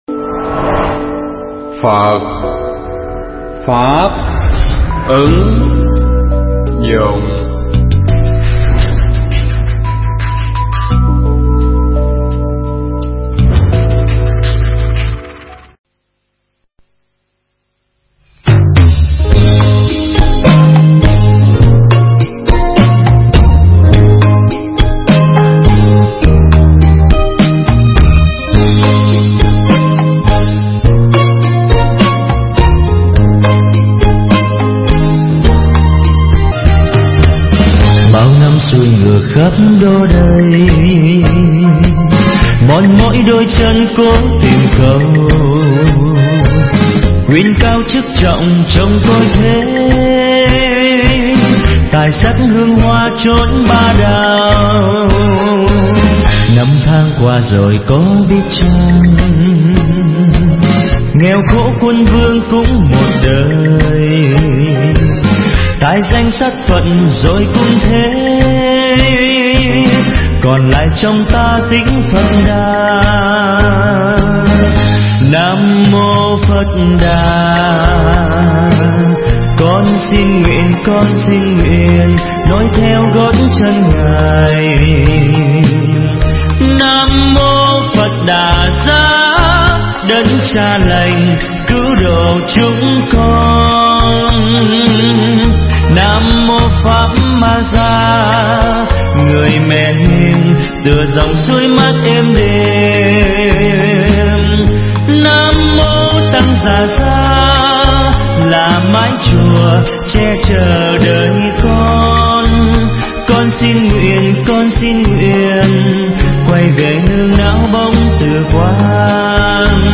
pháp thoại Buông Tất Cả Sẽ Được Tất Cả
thuyết pháp tại Thiền Viện Minh Quang (Úc Châu)